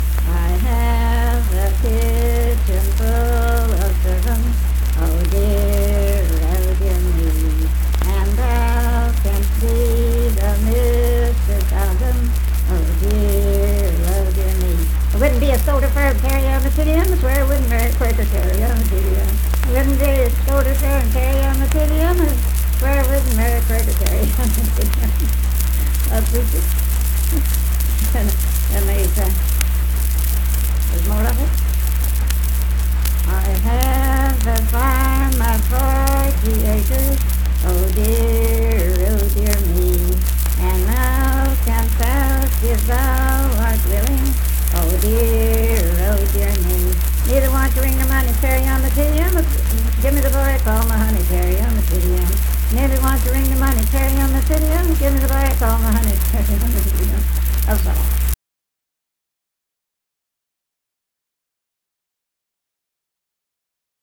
Unaccompanied vocal music performance
Dance, Game, and Party Songs
Voice (sung)